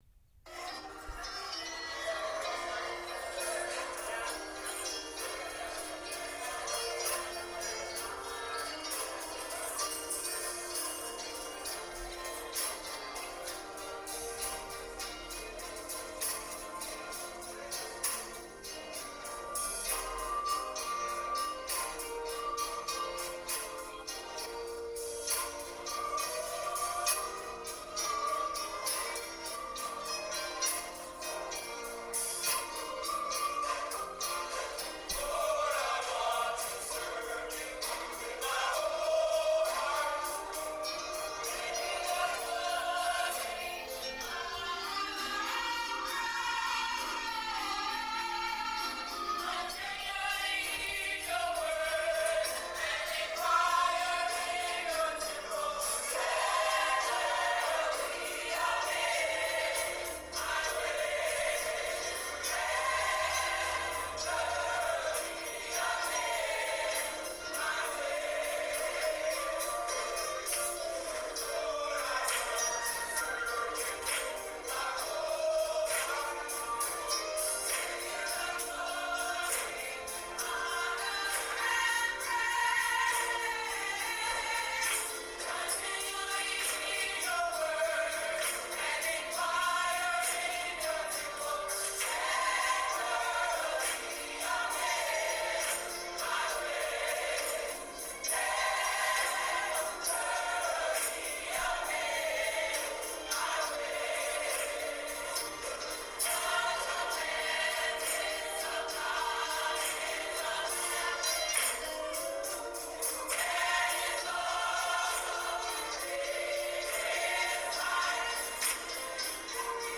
Bb 4/4 80
Choir